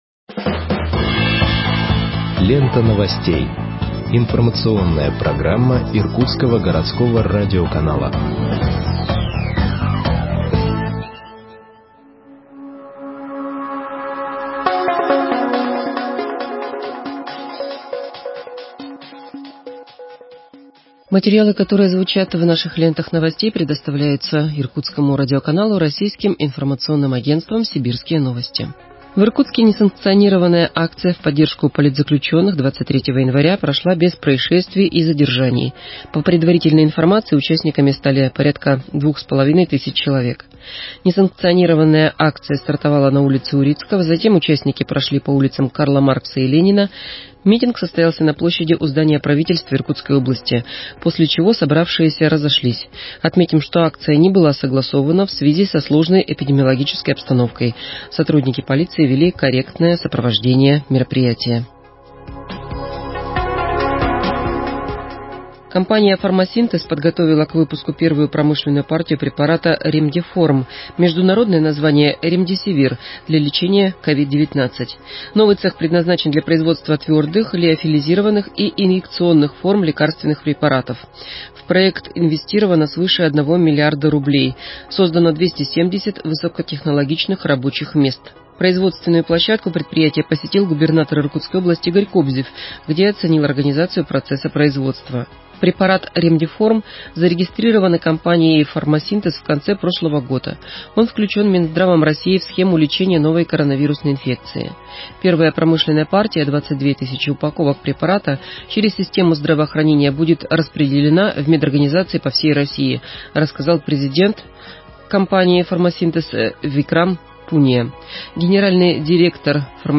Выпуск новостей в подкастах газеты Иркутск от 26.01.2021 № 1